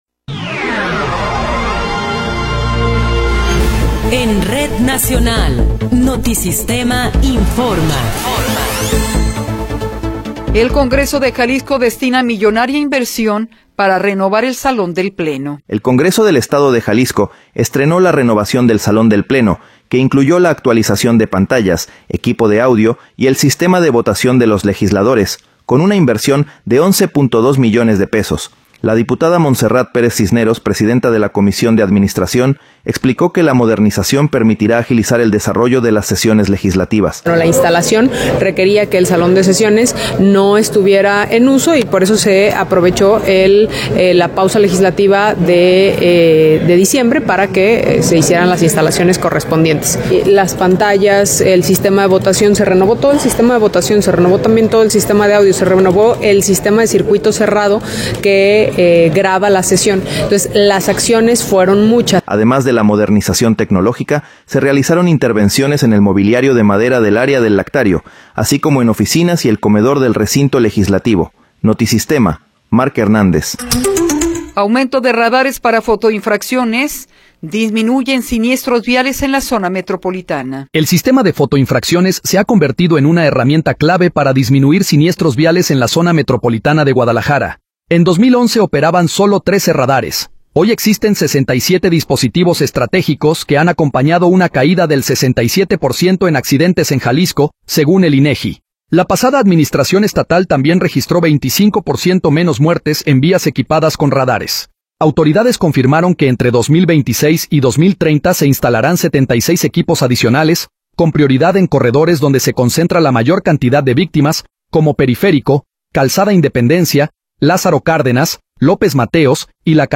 Noticiero 10 hrs. – 16 de Enero de 2026